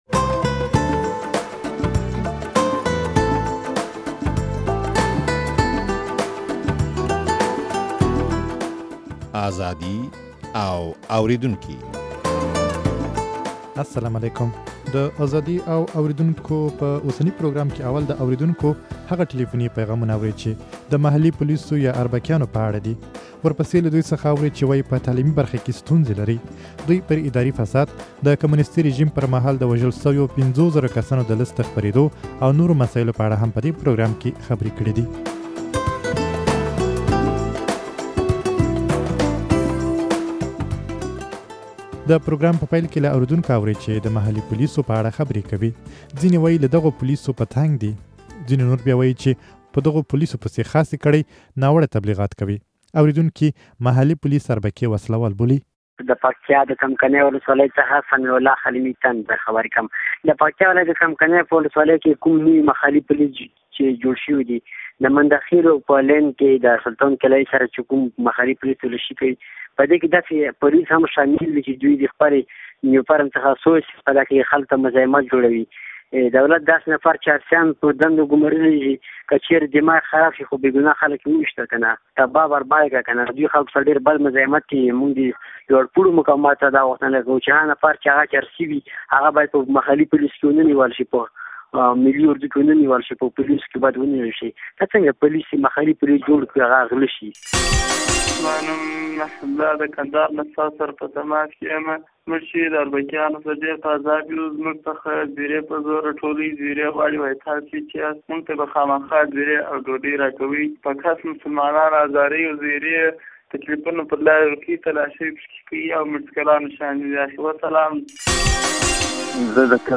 د ازادي او اورېدونکو په اوسني پروګرام کې اول د اورېدونکو هغه ټليفوني پيغامونه اورئ چې د محلي پوليسو يا اربکيانو په اړه دي.